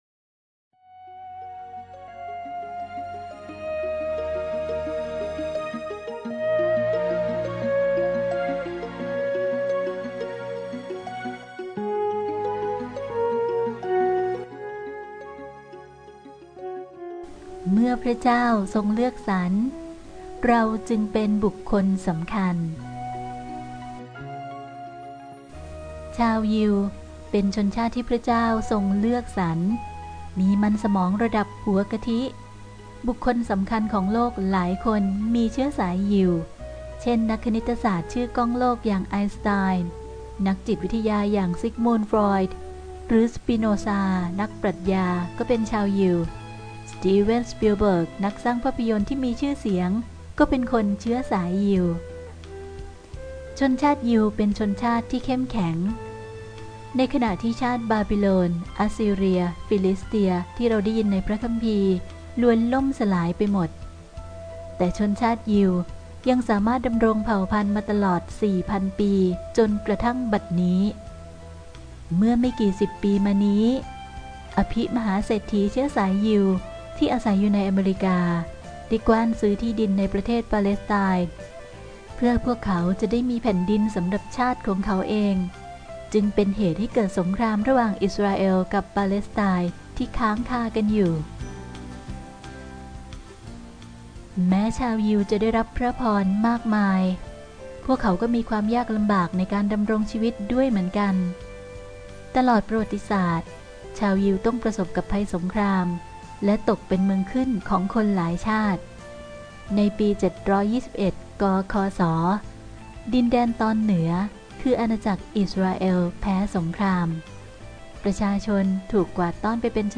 เสียงอ่านโดย